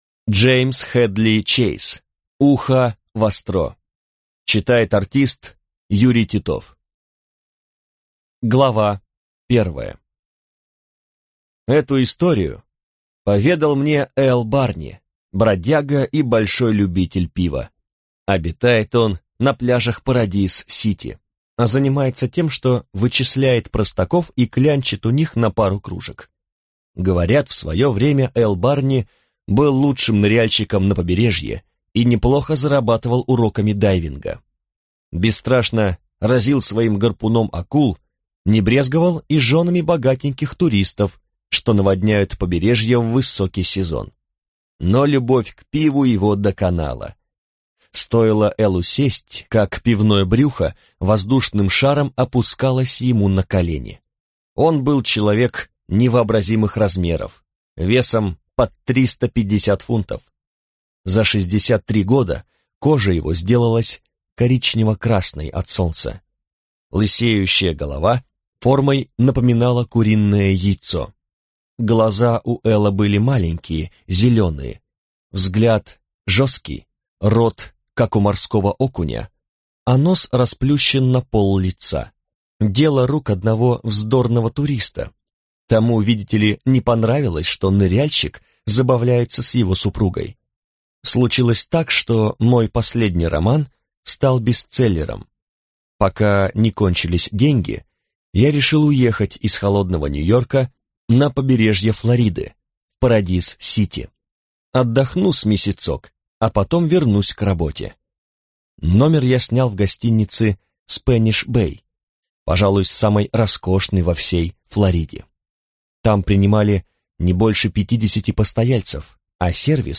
Аудиокнига Ухо востро | Библиотека аудиокниг